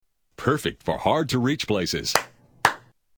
Tags: The Clapper The Clapper clips The Clapper sounds The Clapper ad The Clapper commercial